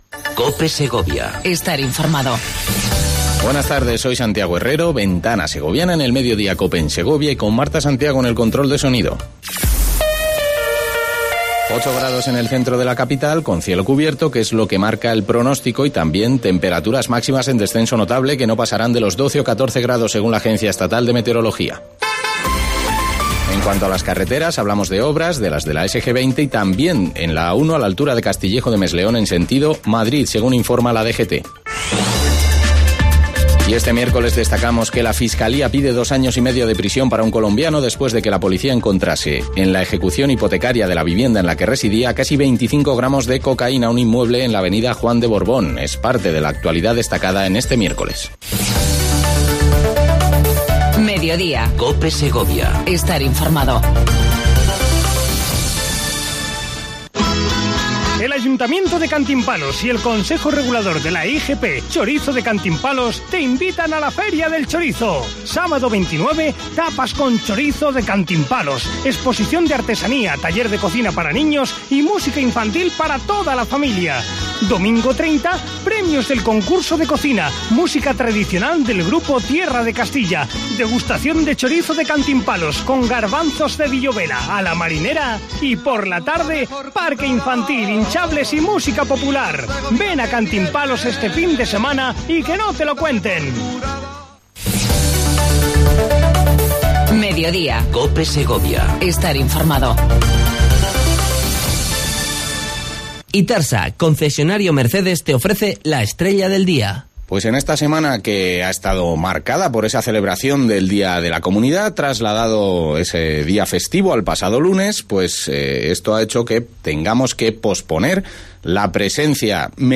Entrevista con la Subdelegada de gobierno, Pilar Sanz